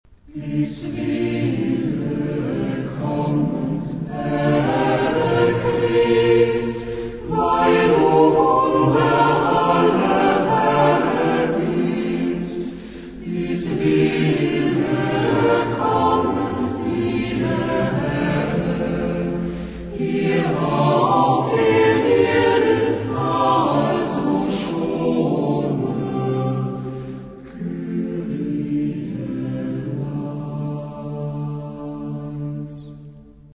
Genre-Style-Forme : Chanson ; Folklore
Type de choeur : SATB  (4 voix mixtes )
Tonalité : mode de ré